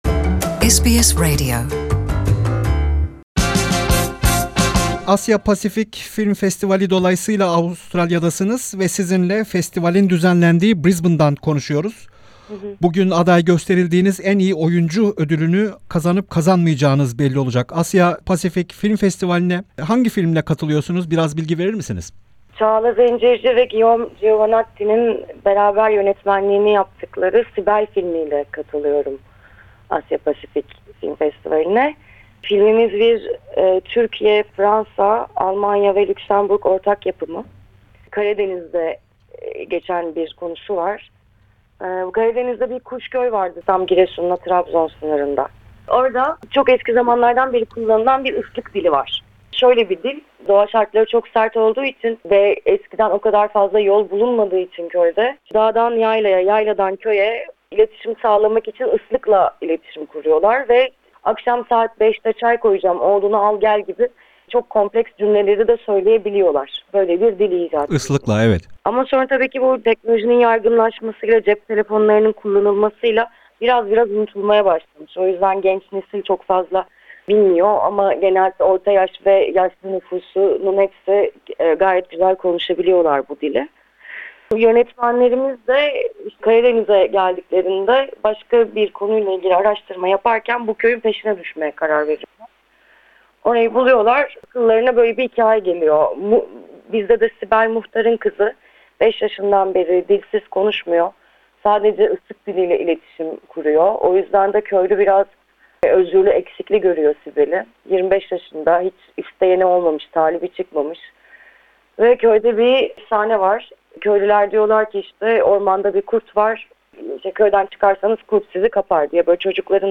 Brisbane'da düzenlenen Asya Pasifik Film Festivali'nde en iyi kadın oyuncu ödülüne aday gösterilen Damla Sönmez ile konuştuk.